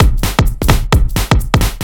OTG_Kit 4_HeavySwing_130-C.wav